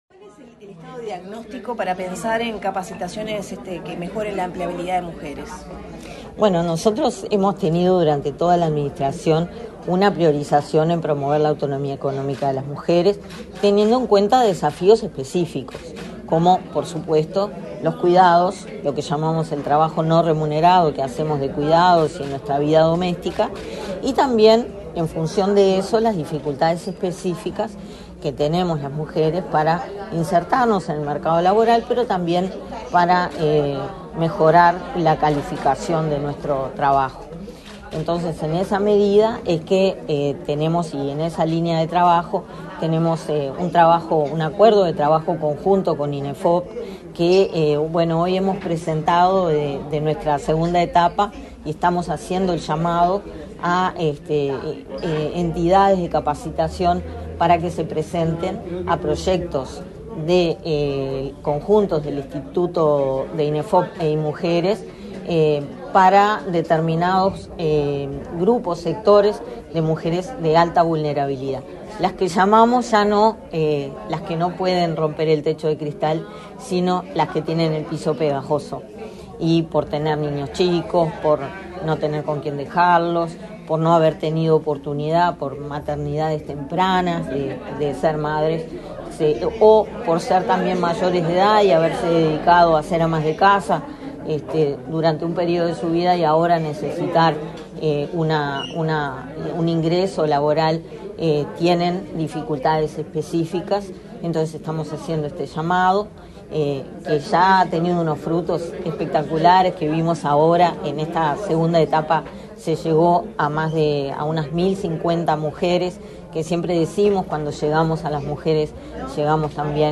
Declaraciones a la prensa de la directora de Inmujeres del Mides, Mónica Bottero